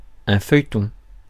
Ääntäminen
Synonyymit télésérie Ääntäminen France: IPA: [fœj.tɔ̃] Haettu sana löytyi näillä lähdekielillä: ranska Käännös Ääninäyte Substantiivit 1. soap opera 2. serial 3. essay US 4. column US 5. magazine section Suku: m .